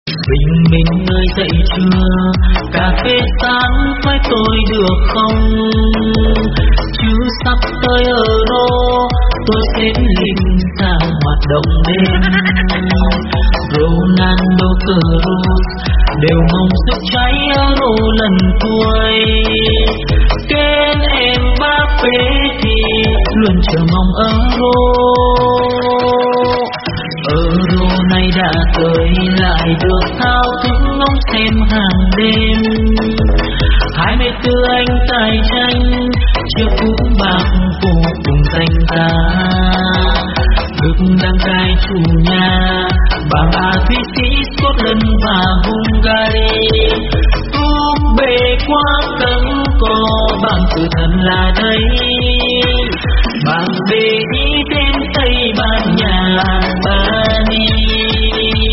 Nhạc Chuông Chế Hài Hước